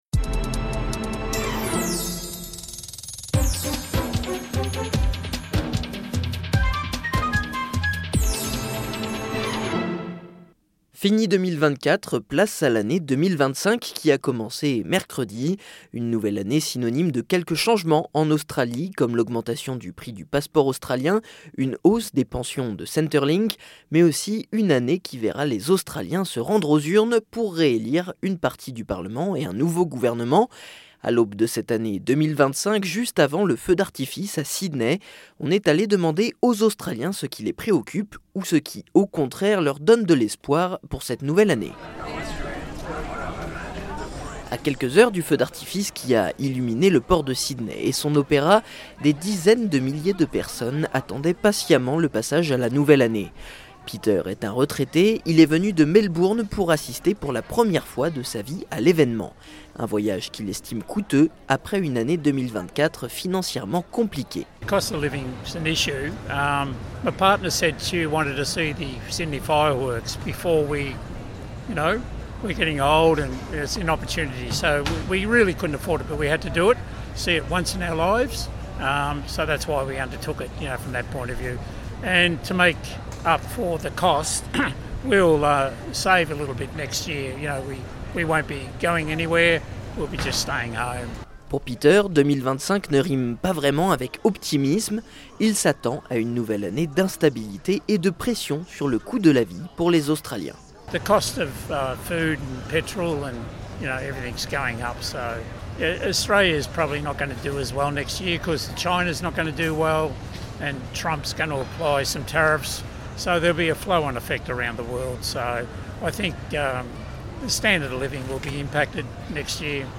À l'heure du passage à la nouvelle année, plusieurs Australiens nous ont confié leurs espoirs et leurs craintes pour 2025.